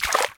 Footsteps_Water_3.ogg